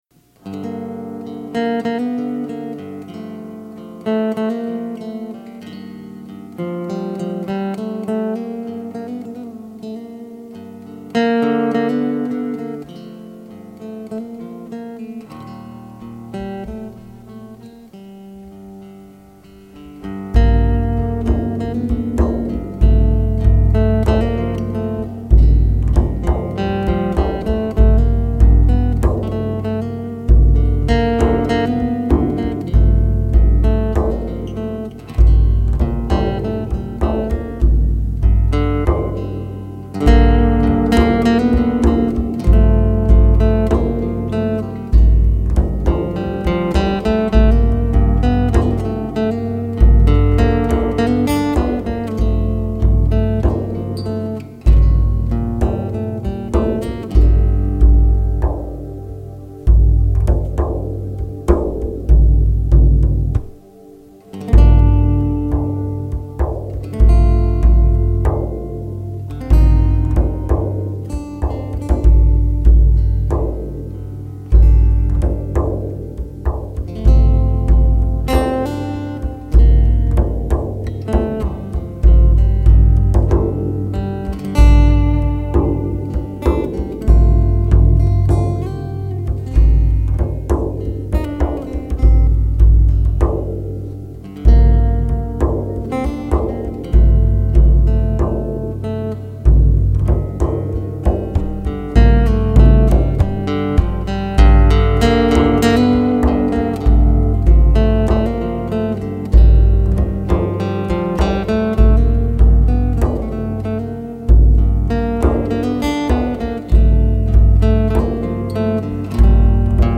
Live at Tupelo Music Hall 11/16/06